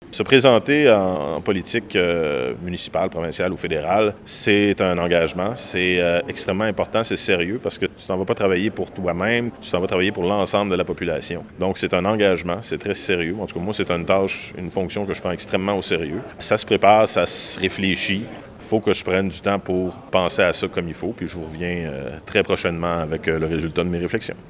Interrogé mardi soir lors d’un événement public, il a indiqué qu’il compte prendre le temps nécessaire pour décider s’il se représente ou non à la mairie lors de l’élection municipale du 2 novembre prochain.